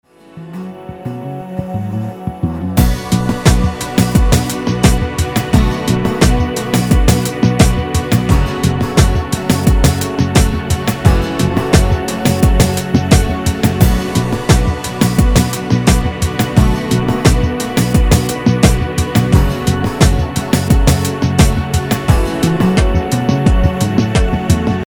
Звук нравится, но интрументал не тот для хип-хопа: бочка сухая, лёгкая, почти без тела .. про остальное написано Примеры (присутствует ненормативная лексика):